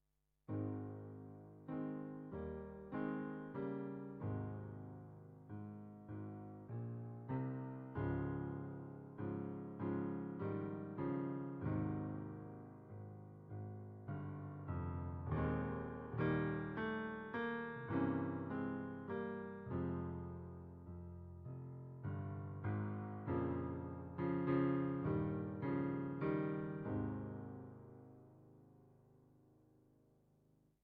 Same thing in A-flat instead of B-flat. See what I mean about it getting too deep, where the chords start to sound weird? The lower you go, the less notes you can have in the chord.